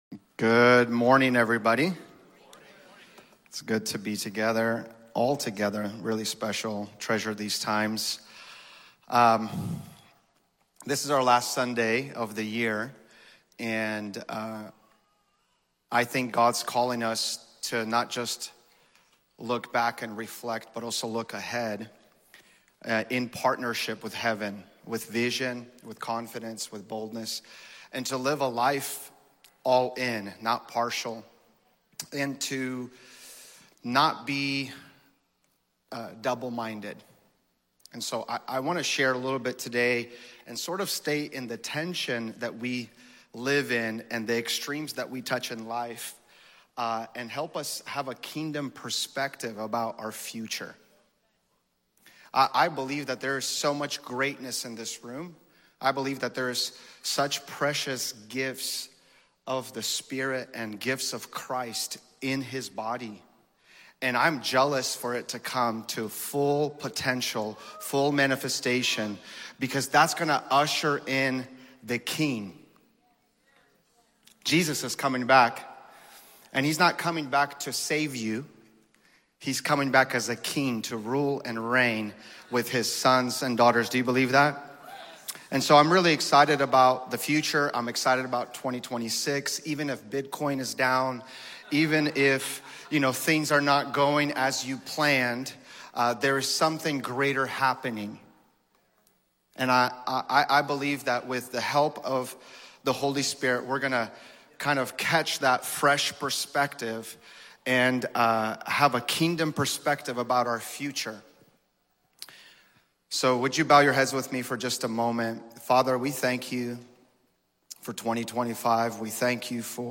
A message on stewarding what God has entrusted to us, calling us to live all-in with responsibility, faith, and a kingdom perspective for the future.